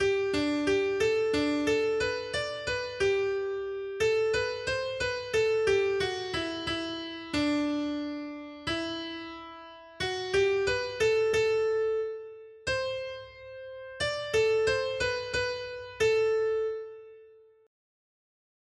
Noty Štítky, zpěvníky ol38.pdf responsoriální žalm Žaltář (Olejník) 38 Ž 97, 1-12 Skrýt akordy R: Světlo dnes zazáří nad námi, neboť se nám narodil Pán. 1.